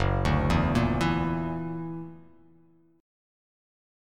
Gb+7 chord